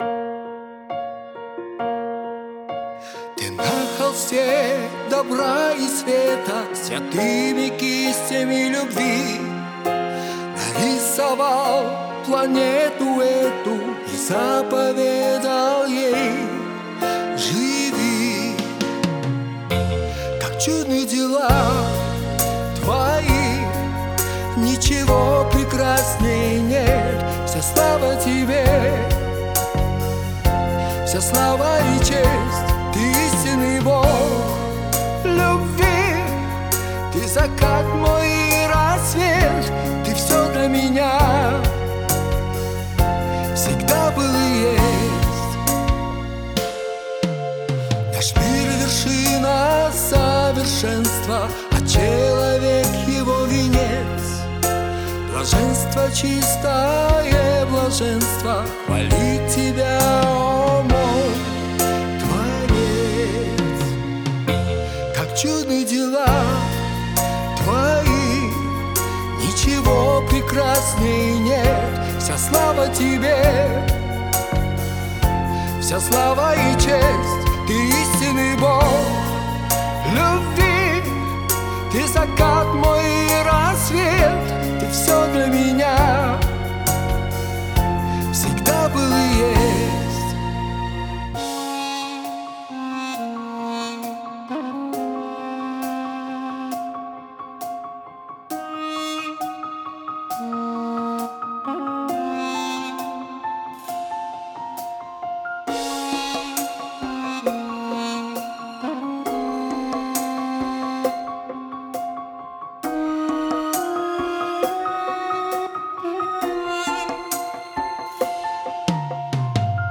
304 просмотра 134 прослушивания 29 скачиваний BPM: 67